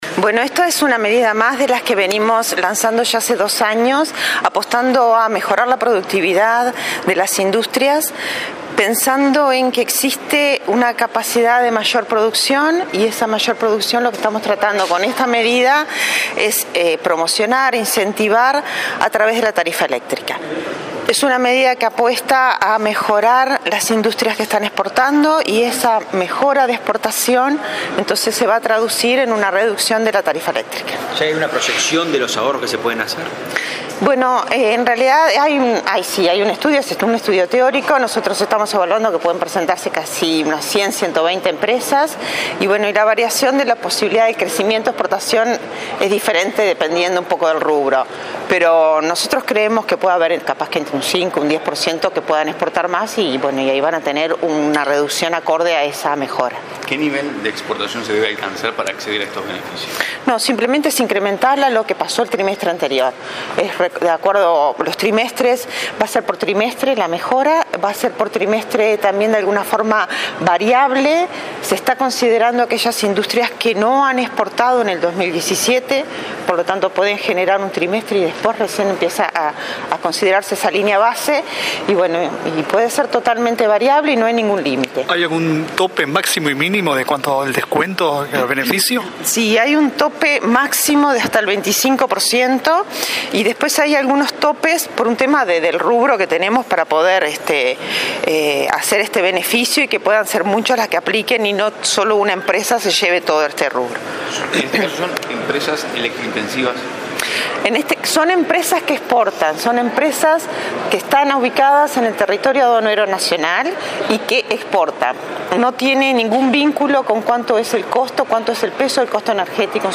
El Ministerio de Industria prevé que entre 100 y 120 empresas que aumentaron sus exportaciones en el primer trimestre del año tendrán descuentos de hasta 25 % en la tarifa de UTE. El beneficio abarca a todos los sectores industriales y a las firmas que mejoraron sus ventas al exterior, indicó la directora nacional de Energía, Olga Otegui, al presentar este jueves en el Cabildo este beneficio establecido por el Ejecutivo.